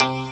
Hits